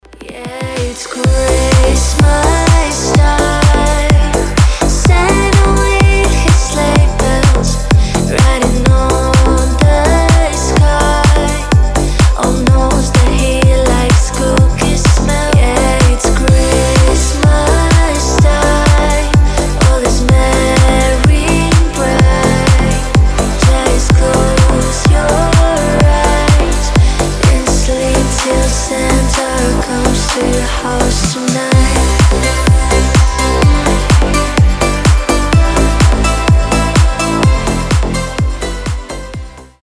Уютная рождественская песня